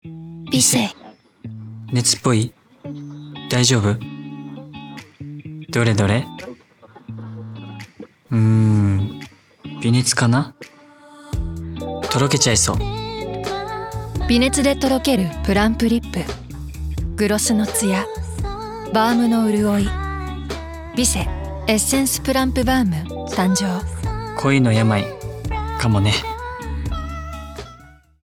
北人の#微熱VOICE
voice-hokuto.wav